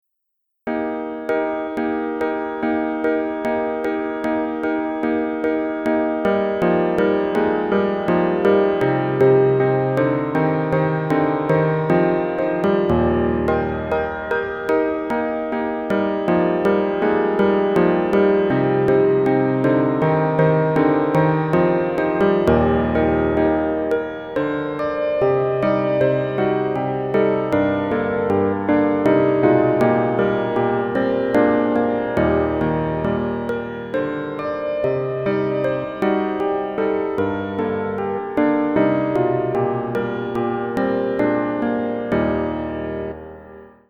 ピアノアレンジ。
クラシック